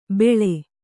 ♪ beḷe